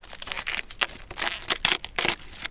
ALIEN_Insect_04_mono.wav